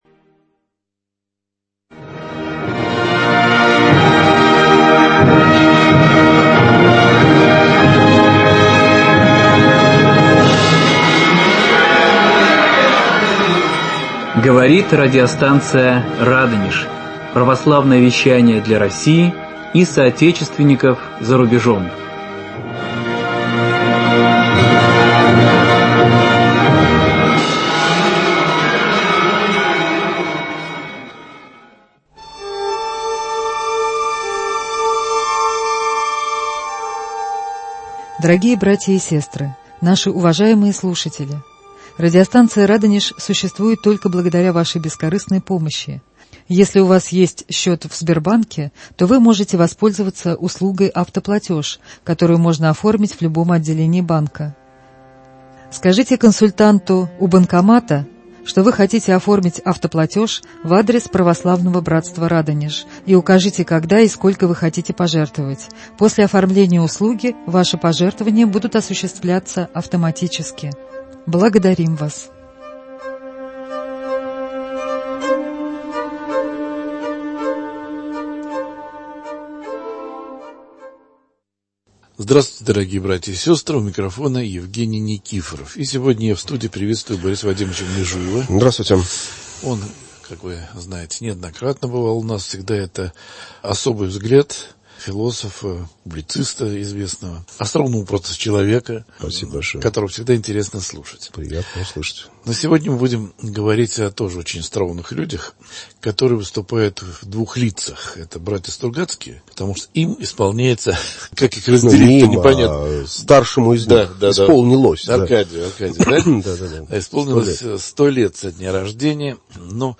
Столетию со дня рождения Аркадия Стругацкого посвящена эта беседа